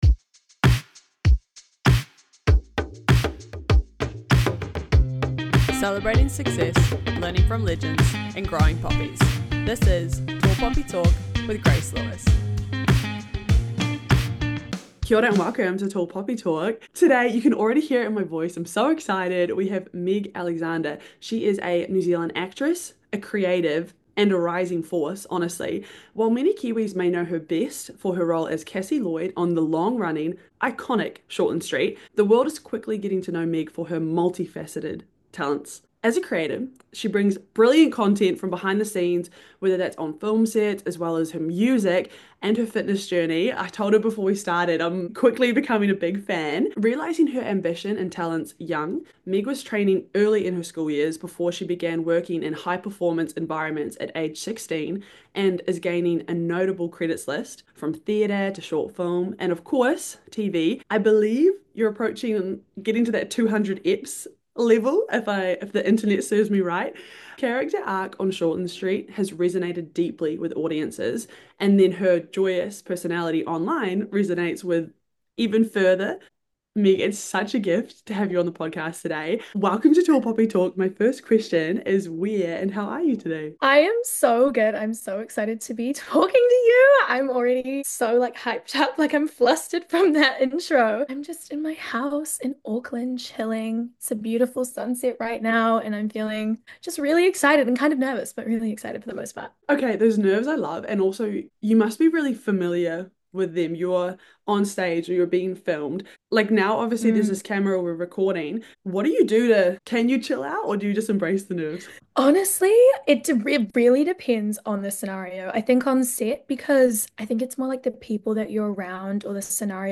Listen to the full interview on Spotify, iHeart Radio, or Apple Podcasts.